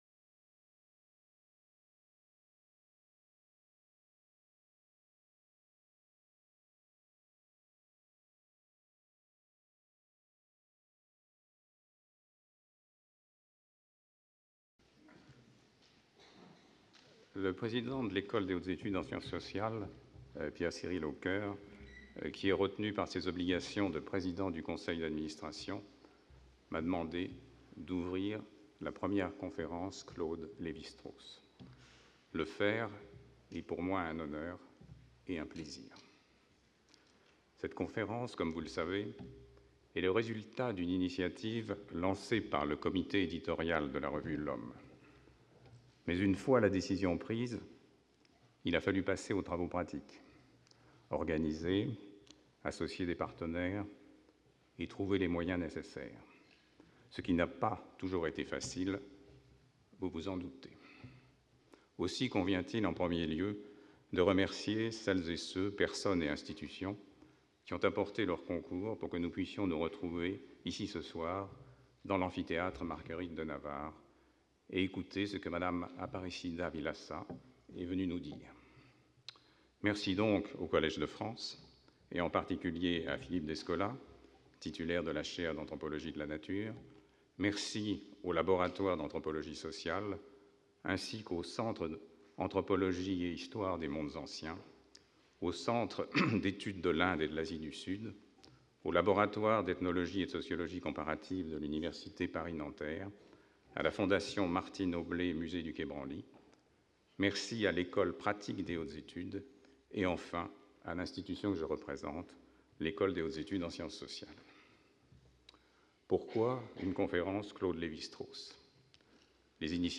Première Conférence Lévi-Strauss
Introduction : François Hartog, historien (EHESS) Philippe Descola, anthropologue (EHESS / Collège de France) Collège de France (amphithéâtre Marguerite de Navarre)